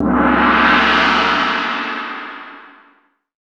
Index of /90_sSampleCDs/300 Drum Machines/Akai MPC-500/3. Perc/ChinesePrc